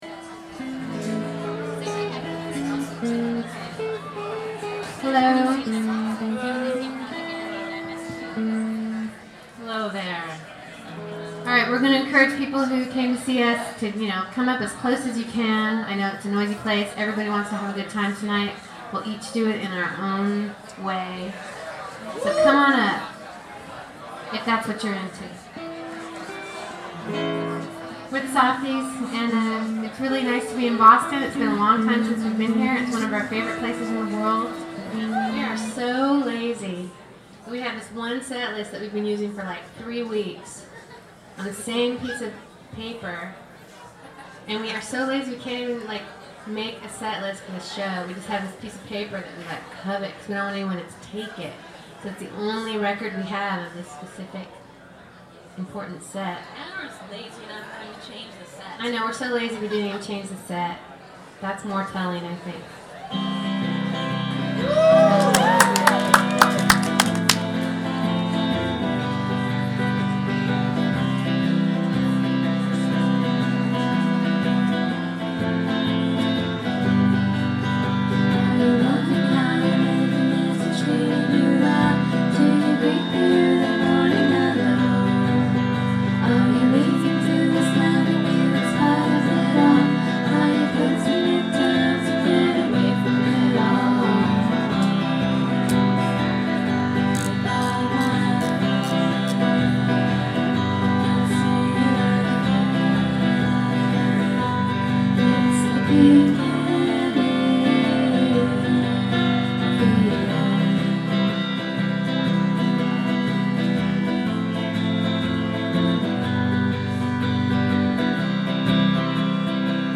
Today we have a live recording of